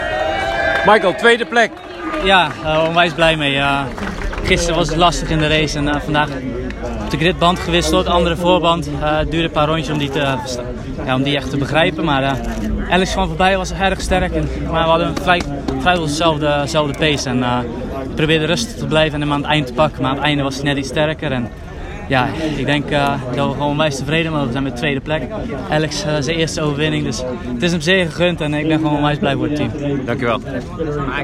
Na afloop van de race spraken we met Van der Mark en vroegen hem om een eerste reactie.